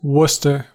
Ääntäminen
UK : IPA : /ˈwʊstə/ US : IPA : /ˈwʊstɚ/